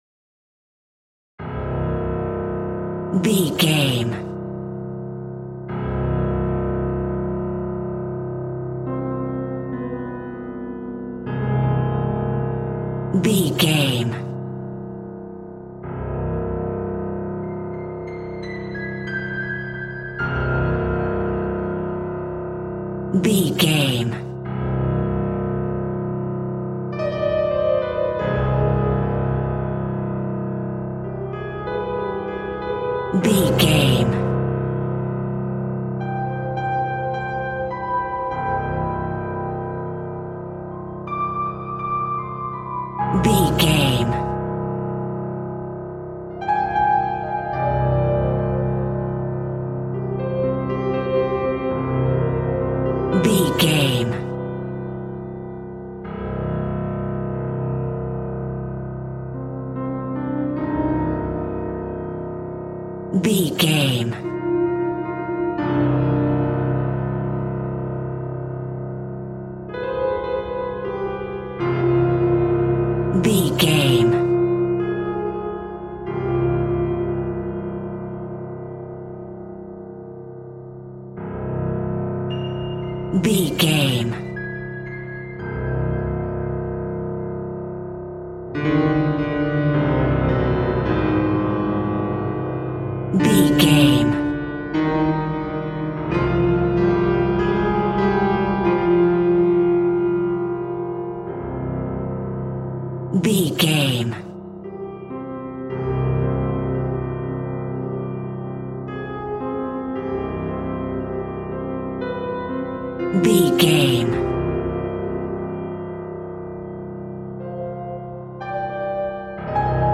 Piano Horror.
Aeolian/Minor
Slow
tension
ominous
dark
haunting
eerie
synth
pads